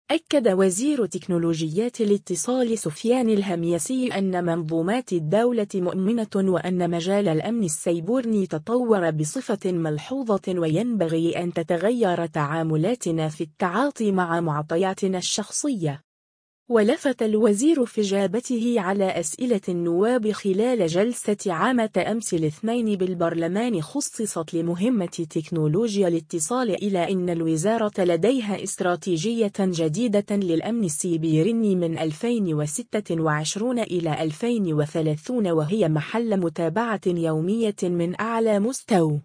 ولفت الوزير في اجابته على أسئلة النواب خلال جلسة عامة أمس الاثنين بالبرلمان خصصت لمهمة تكنولوجيا الاتصال الى ان الوزارة لديها استراتيجية جديدة للأمن السيبيرني 2026-2030 وهي محل متابعة يومية من أعلى مستوى.